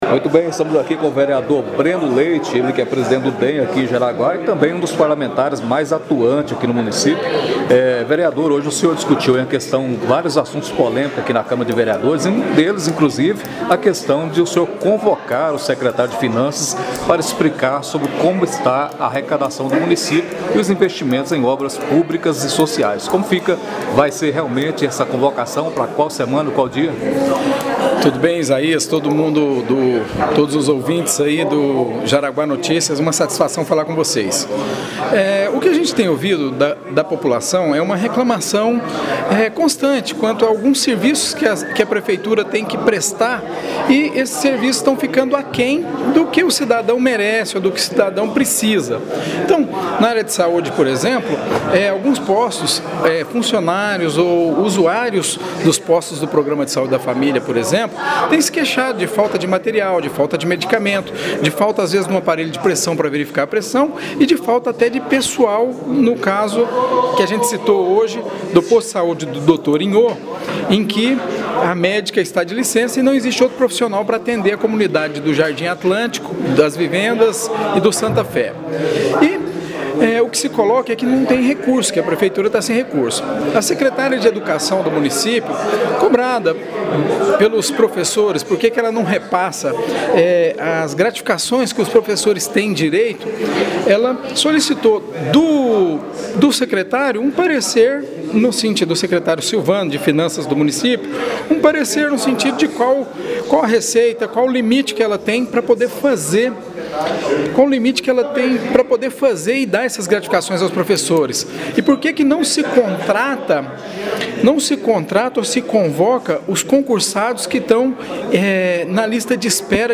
“Não queremos pressionar ou impor. Queremos apenas um esclarecimento do secretário de finança sobre a real situação econômica do município”, disse o médico em entrevista ao Jaraguá Notícia.